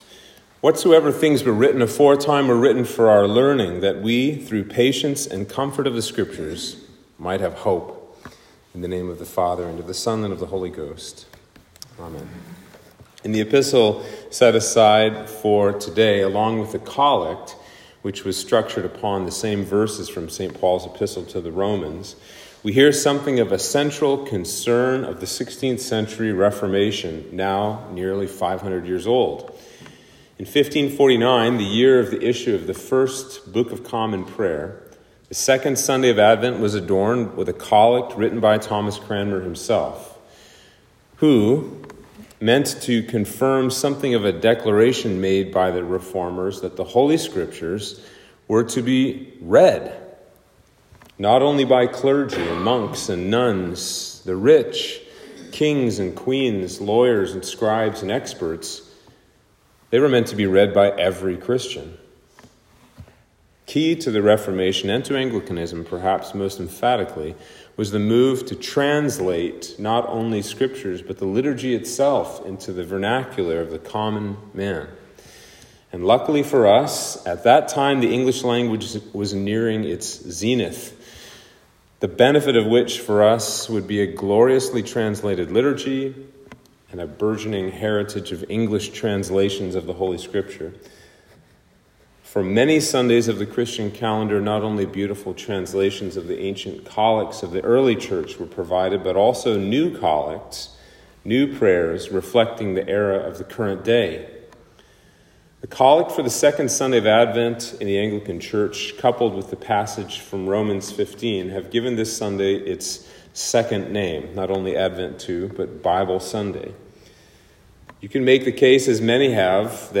Sermon for Advent 2